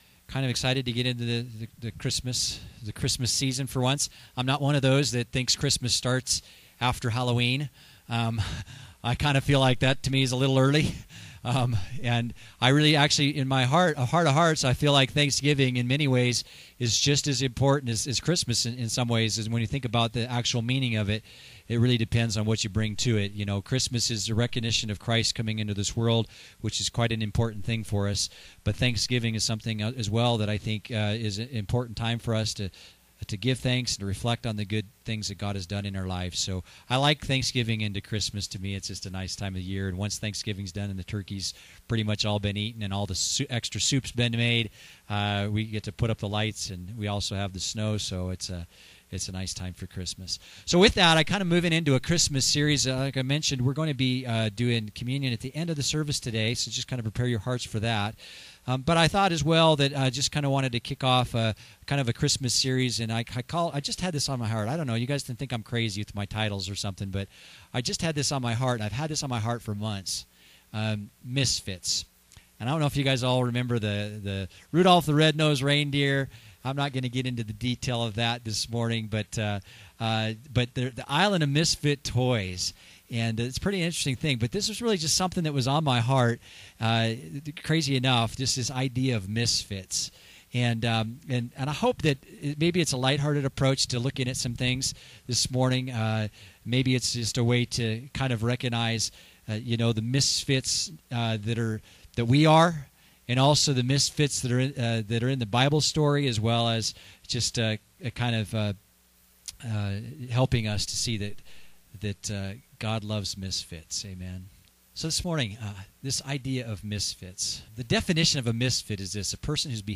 Misfits… A Christmas Message (audio)